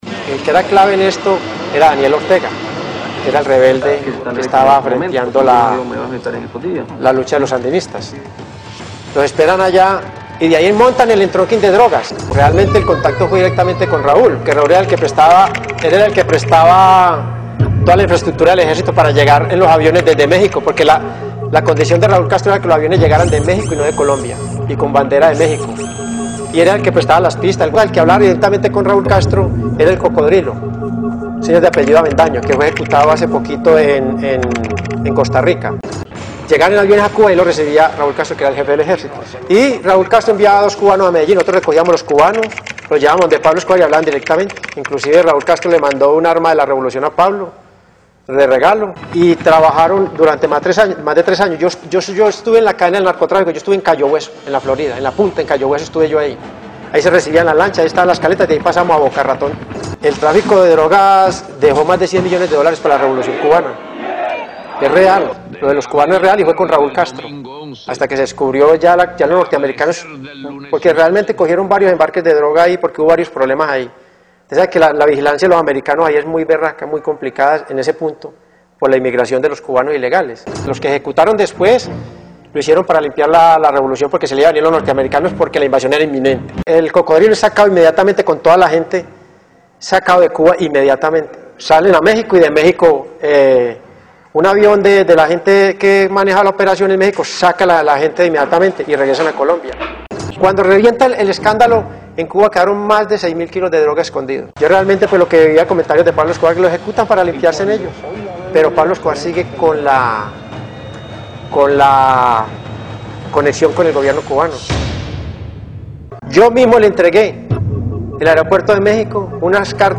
Declaraciones de "Popeye" sobre la conexión cubana en un reportaje de Telemundo 51